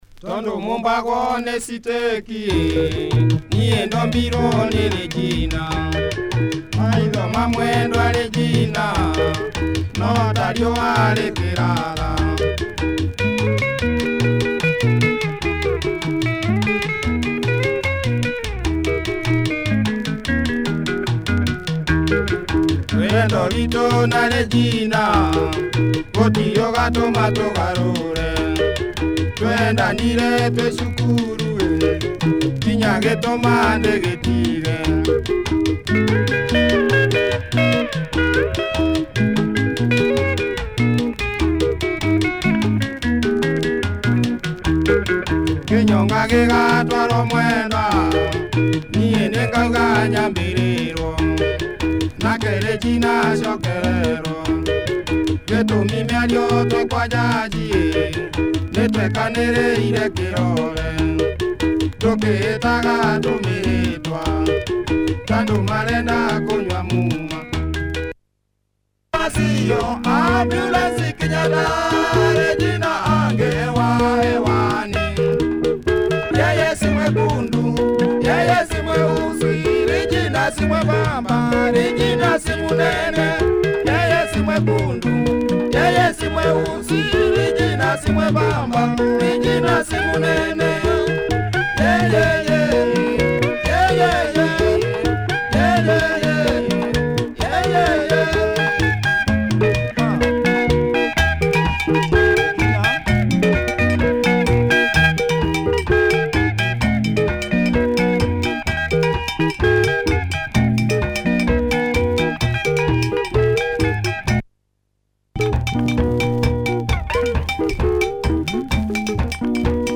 Nice chunky benga from this great kikuyu group